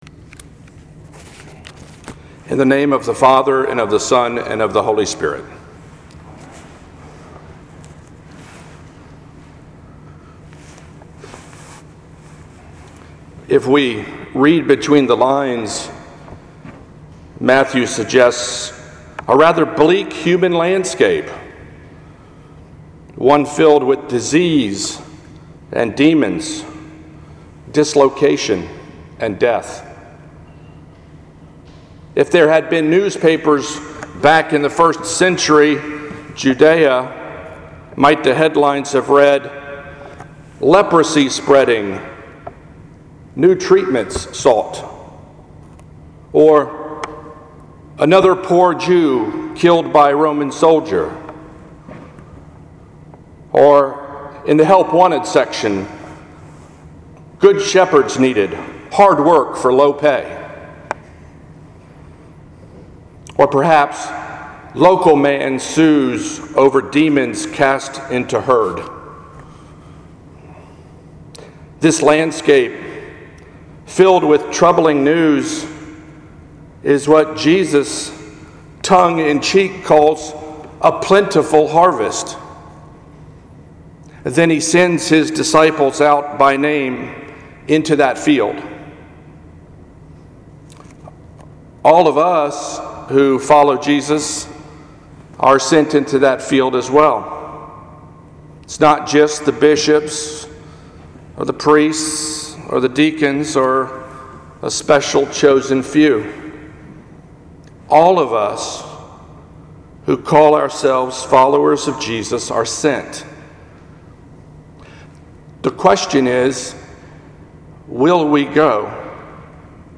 In today’s sermon from the 8:00 a.m. service